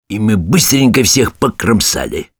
Снайпер снимает шляпу, держа её на груди и бормочет с торжественным выражением: